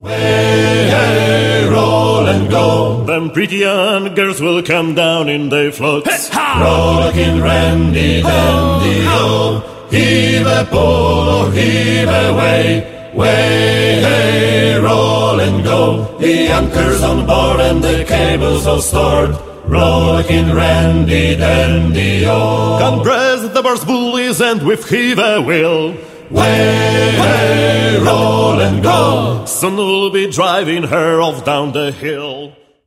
(sł. i mel. trad.)